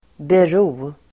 Uttal: [ber'o:]